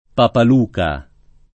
[ papal 2 ka ]